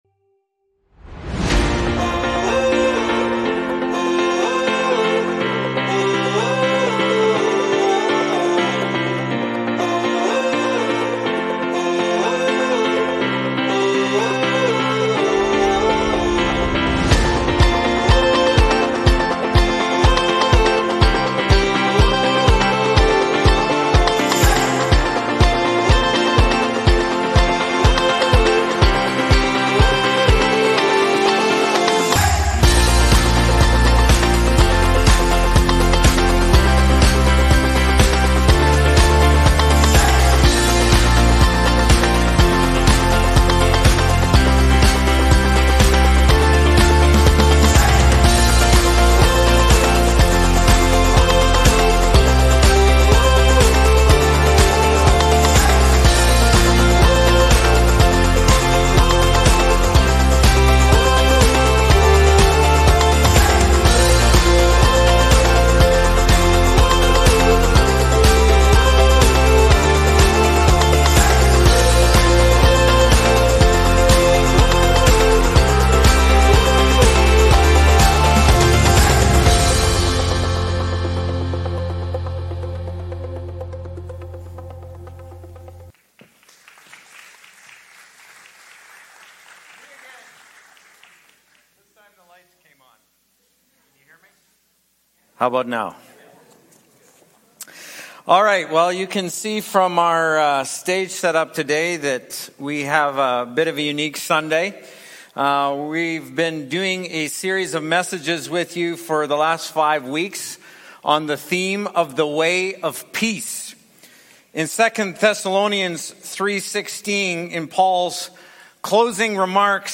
group panel discussion